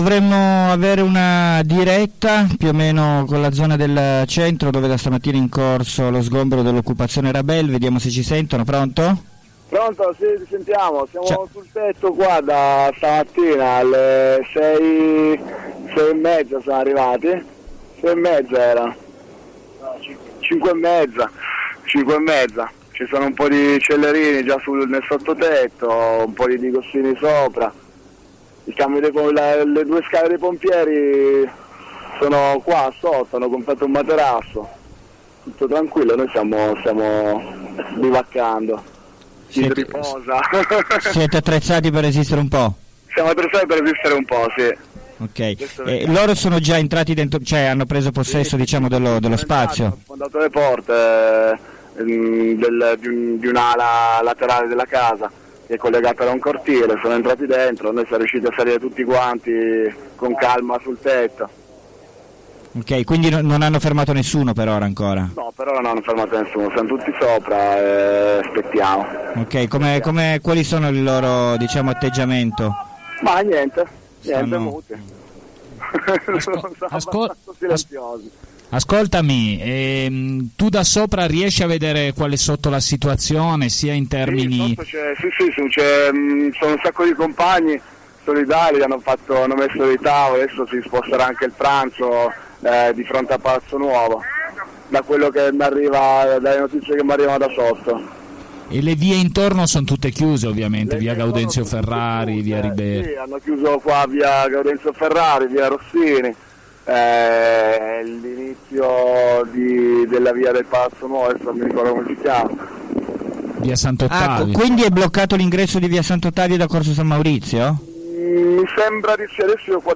diretta_Rabel
rabel_1a_diretta.mp3